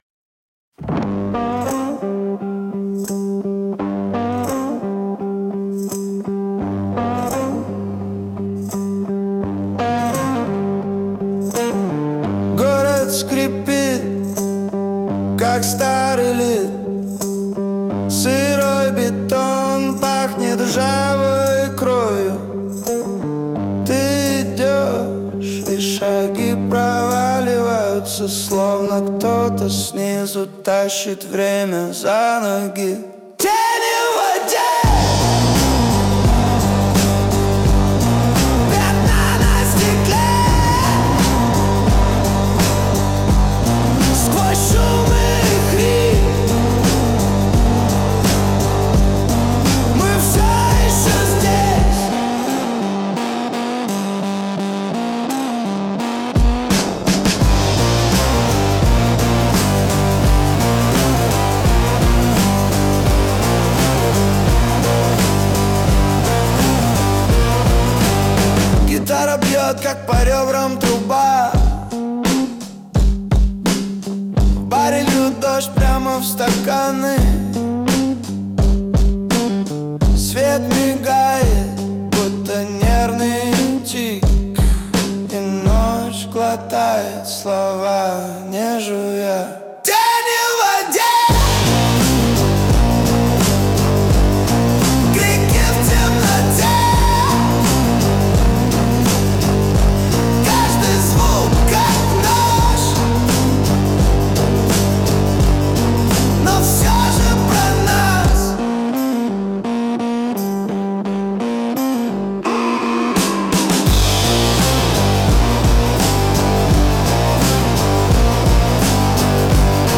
Тени в воде (панк-блюз версия)
• Исполняет: Поставторcкий арт
postawtor-teni_w_wode_pank_bljuz_wersija-2.mp3